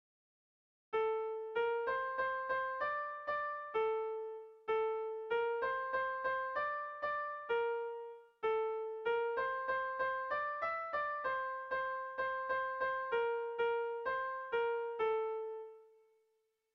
Sehaskakoa
A1A2